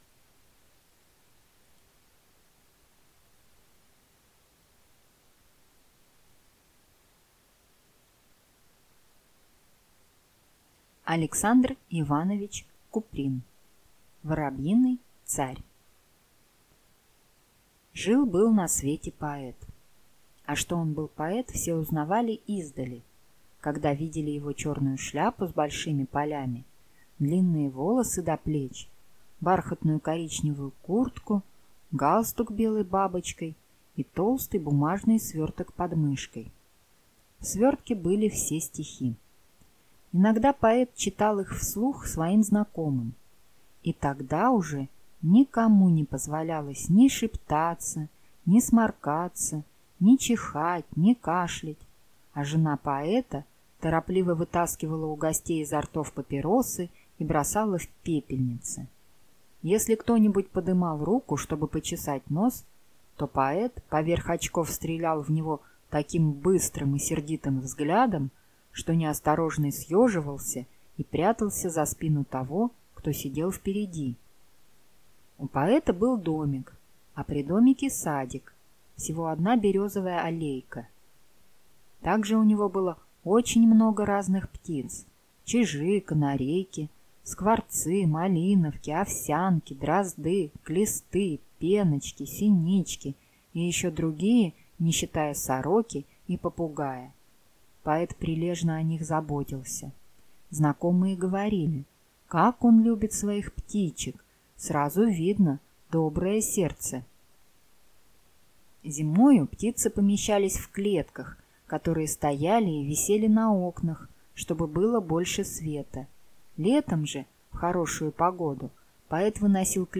Аудиокнига Воробьиный царь | Библиотека аудиокниг